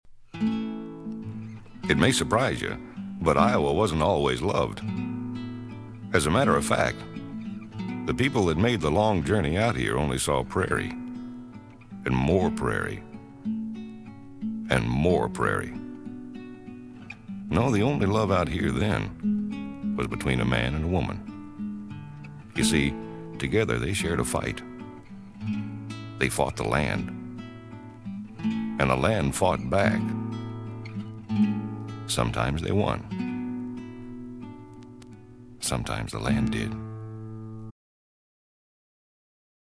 drums
piano
bass guitar
harmonica
banjo
strings
vocals
Folk music--Iowa